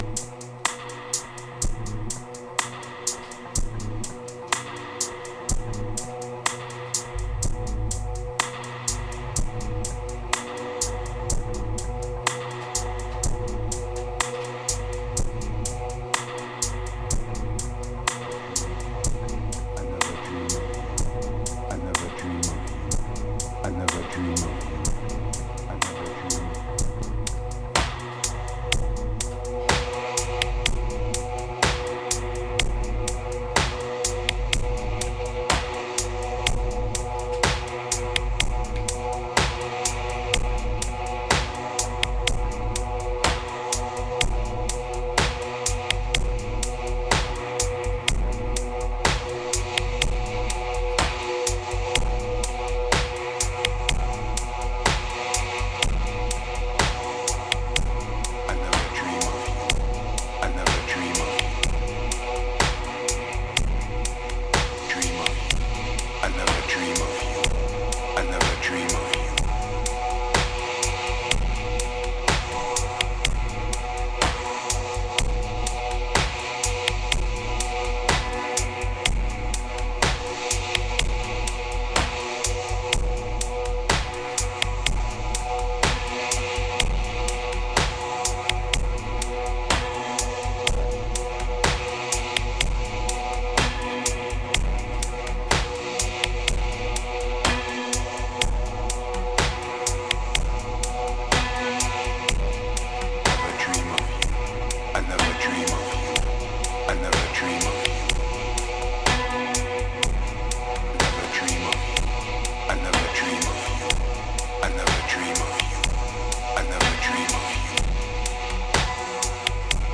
1235📈 - 94%🤔 - 62BPM🔊 - 2023-12-21📅 - 917🌟